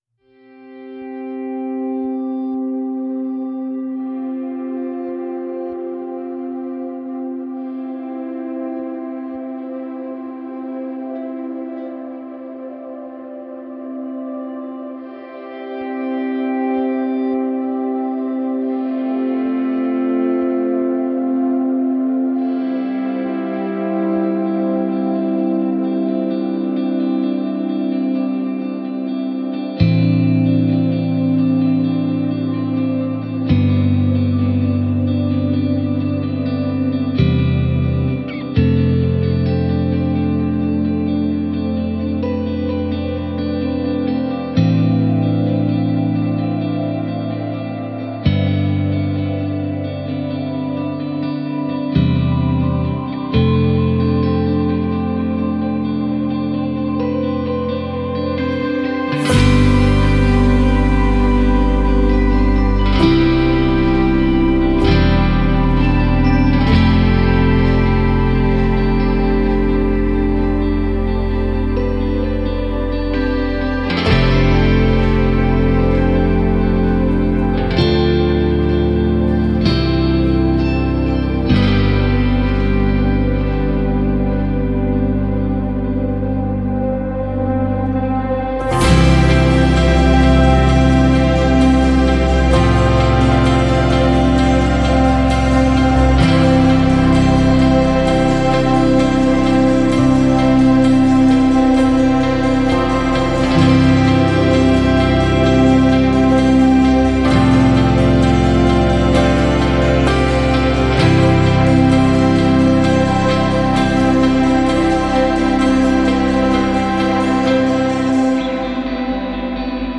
Fracture Sounds Hemisphere Guitars 是一款专业的电吉他采样音源，适合用于电影音乐和其他多种风格的制作。
- 采样了两种经典的电吉他模型，通过不同的放大器和效果器进行深度采样，提供了15种预制的吉他音色，包括手指弹奏、拨弦、泛音等不同的演奏技巧。
- 强调柔和、氛围和电影感的演奏风格，但也可以用于后摇、低保真嘻哈等多种流派。
- 提供了一个氛围层混合器，可以将14种精心制作的声音垫和纹理（由处理过的吉他演奏创建）以任何组合加载到三个可用的插槽中，以创建独特的声音混合。